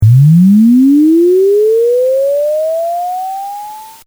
7-bit sine sweep, dithered
In both cases, the dithered signal maintains the sine tone throughout, at the expensive of added “hiss”.
7bit_sweep_dithered.mp3